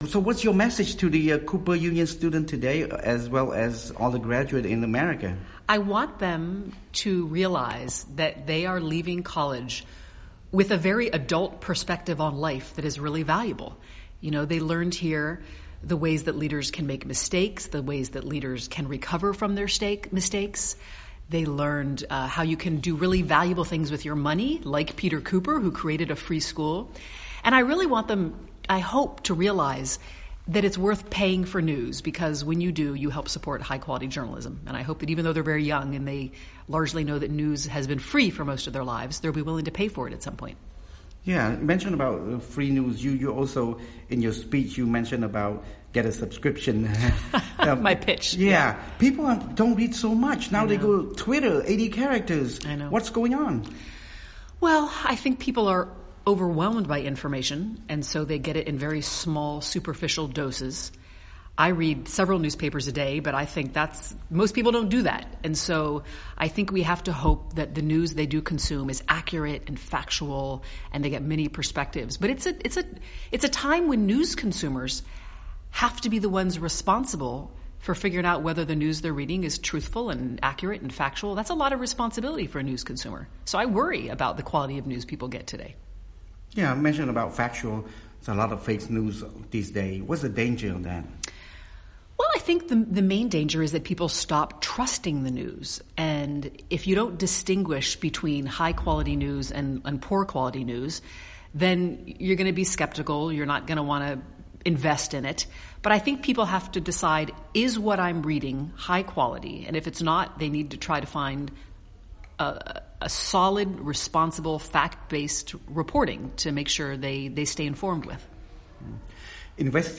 Q&A: Boston Globe Reporter on the Need to Hold the Powerful to Account